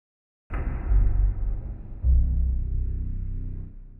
AV_DarkMonster_FX
AV_DarkMonster_FX.wav